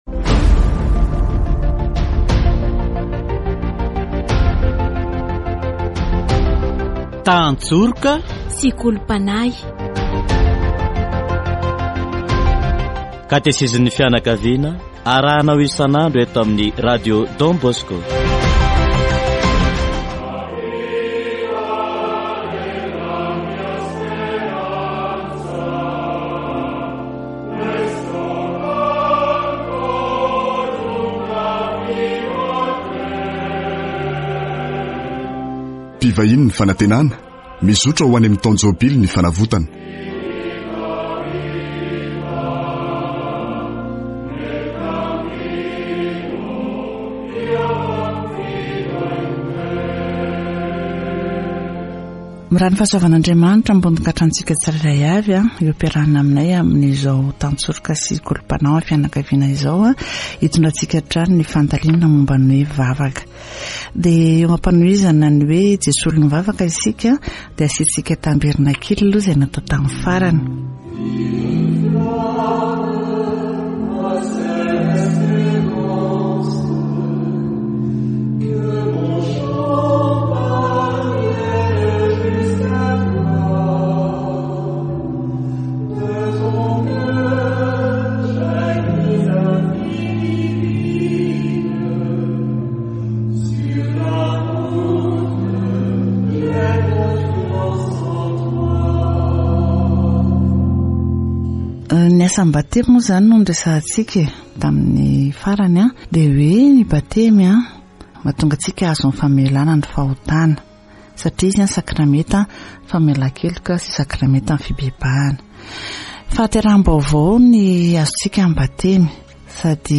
Jesus was not baptised in water to sanctify himself, but he sanctified the water of the Jordan... Catechesis on Jesus